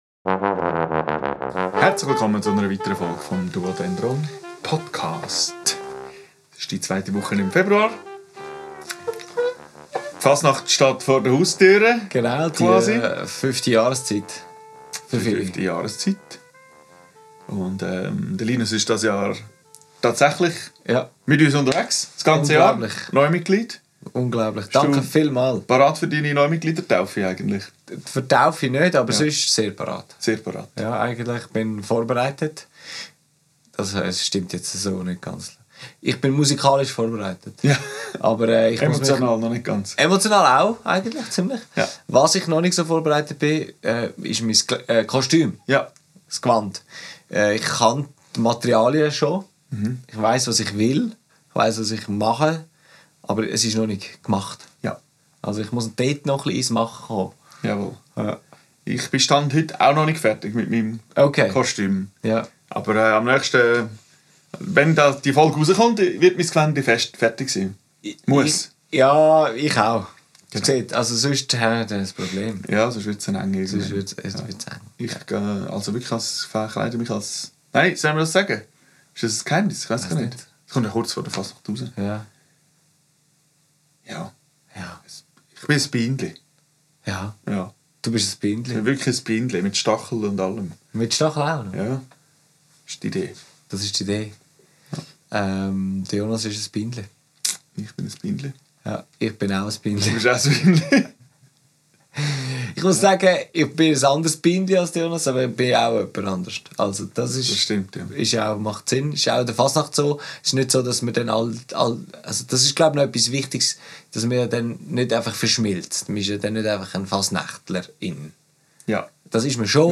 Aufgenommen am 28.01.2026 im Atelier